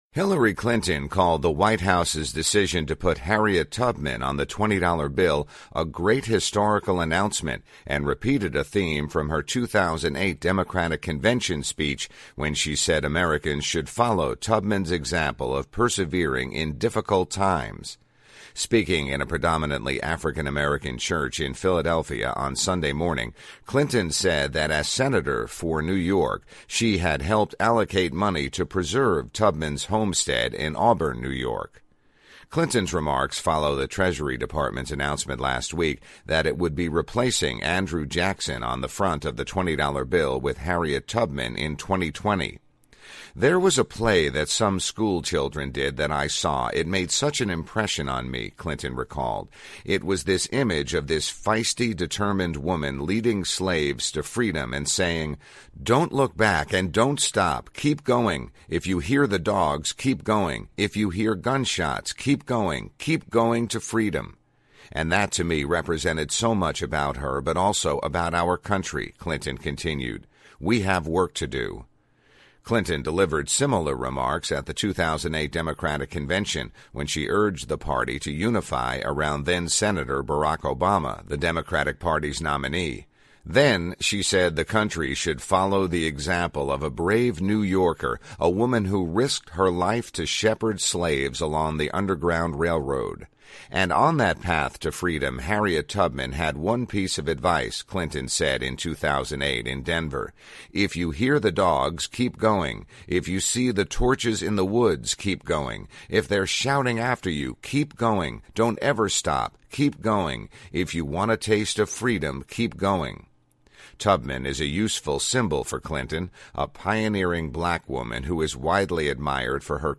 Speaking in a predominantly African-American church in Philadelphia on Sunday morning, Clinton said that as senator for New York, she had helped allocate money to preserve Tubman's homestead in Auburn, NY.